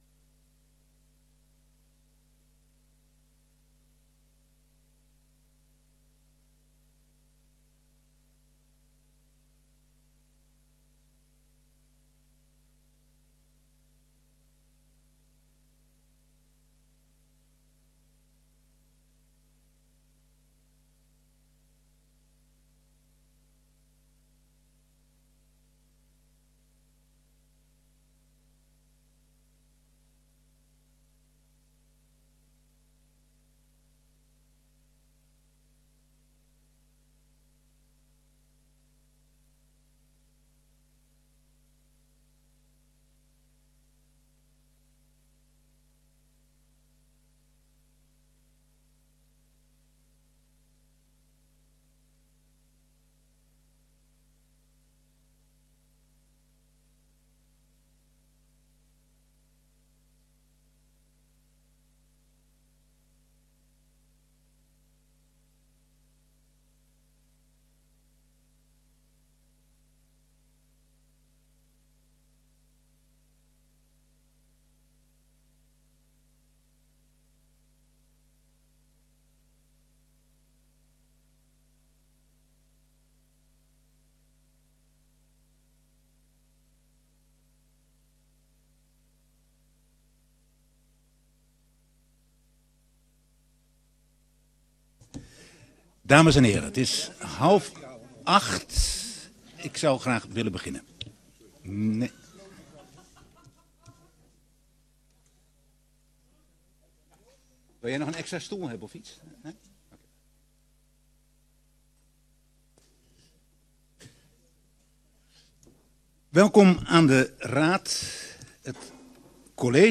De burgemeester van Heemstede roept de leden van de gemeenteraad op tot het houden van een openbare raadsvergadering op 12 februari 2025 in het Raadhuis aan het Raadhuisplein 1 te Heemstede.